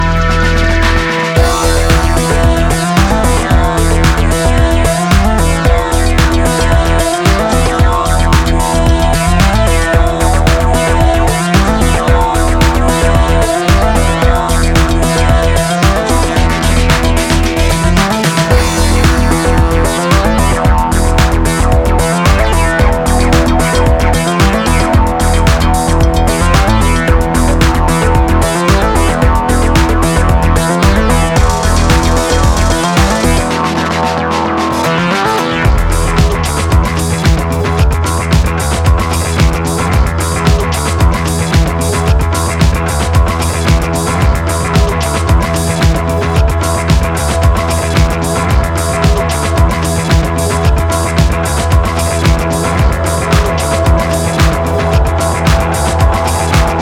electronic duo